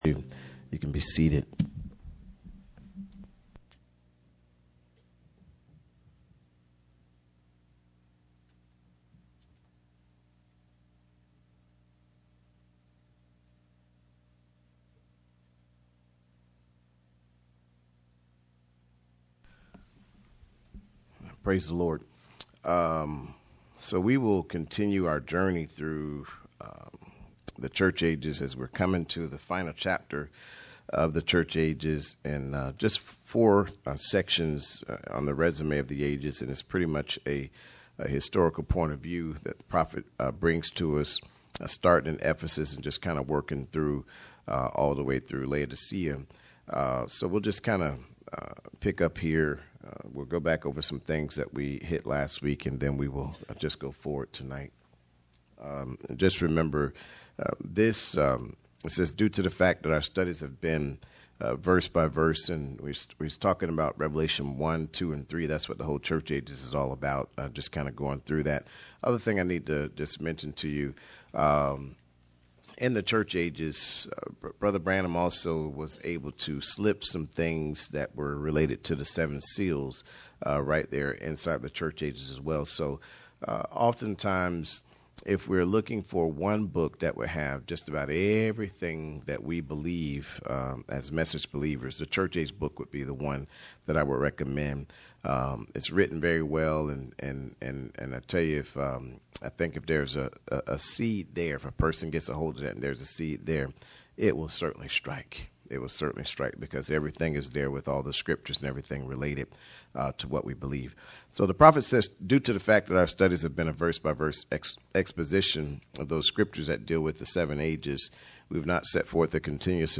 Bible Study Service Type: Midweek Meeting %todo_render% « Church Ages 82